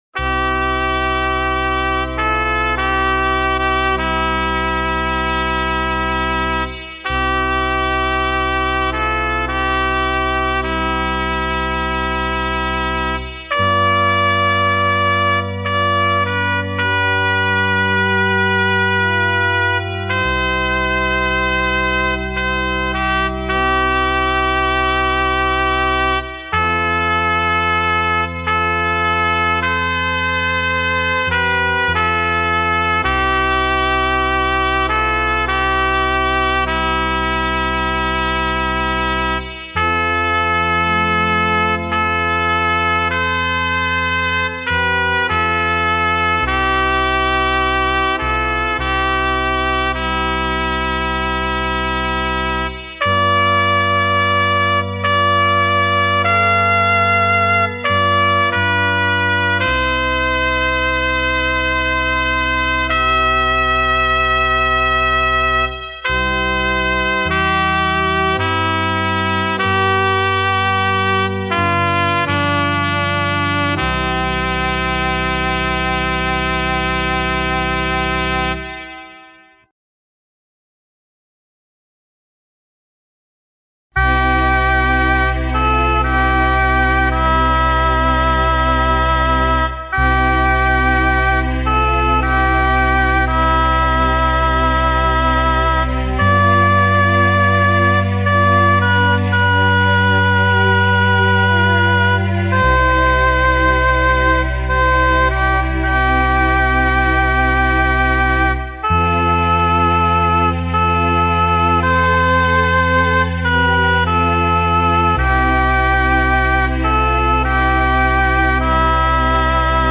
entstanden in Momenten der Stille.
👉🏽 Weihnachtliche Hausmusik E-Orgel YAMAHA EL-25
Weihnachtliche-Hausmusik.mp3